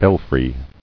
[bel·fry]